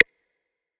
MagicCity Perc 15.wav